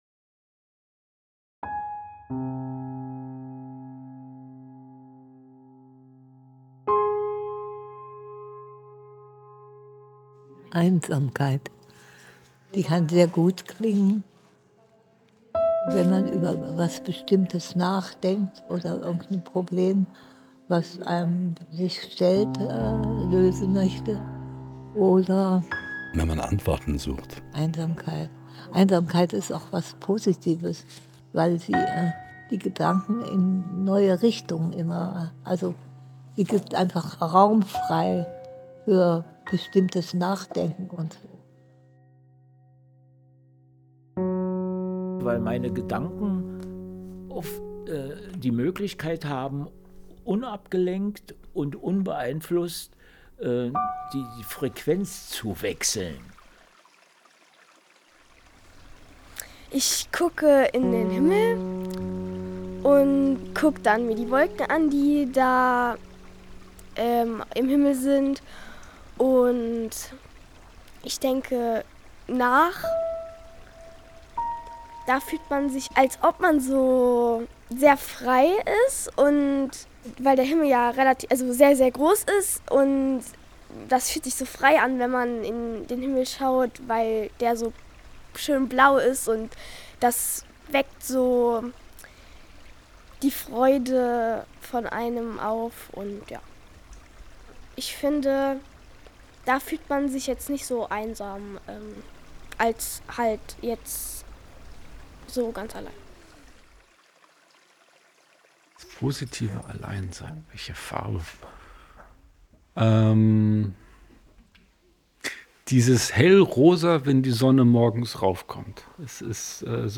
Audioworkshop mit Gedichten
mit Menschen aus der Region Perl, von 8 bis 93 Jahren
Aus den Tonaufnahmen der Gedichte und unserer Gespräche montierten wir zehn Kurzhörstücke.